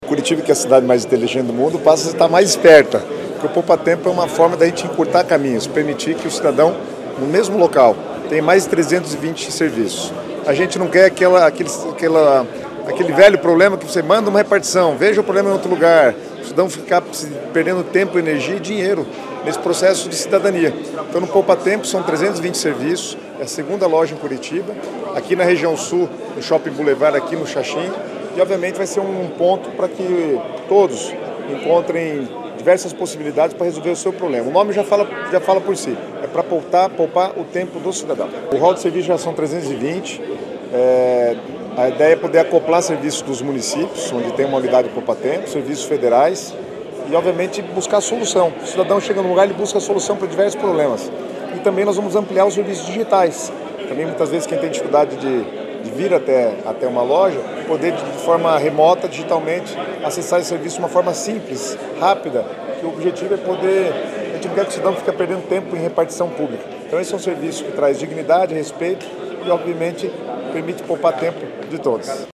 Sonora do secretário das Cidades, Guto Silva, sobre a segunda unidade do Poupatempo Paraná em Curitiba